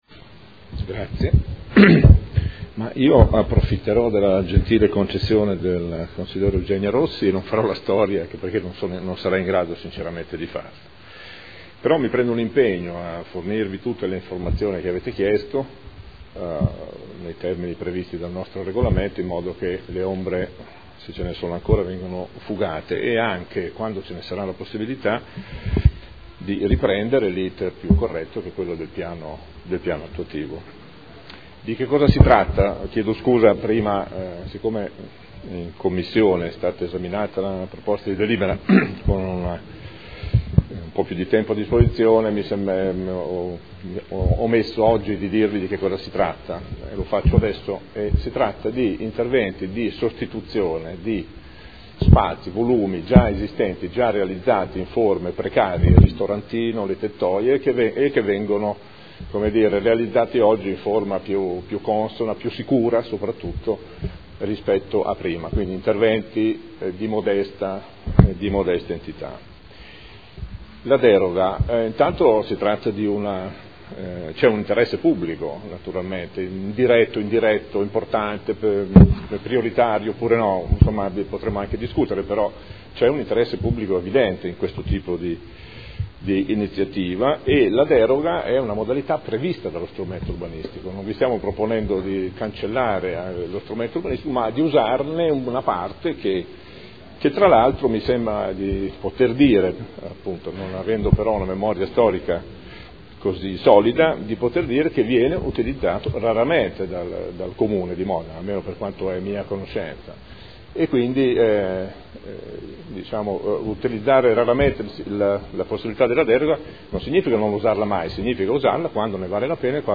Gabriele Giacobazzi — Sito Audio Consiglio Comunale
Seduta del 18/06/2012. Conclude dibattito su proposta di deliberazione.